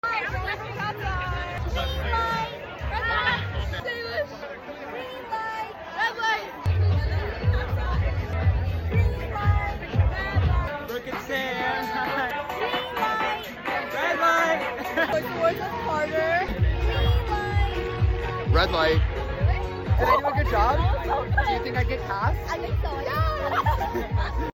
I think they could all pass as the voice of the doll tbh